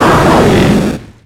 Fichier:Cri 0244 XY.ogg — Poképédia
Cri d'Entei dans Pokémon X et Y.